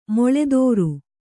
♪ moḷedōru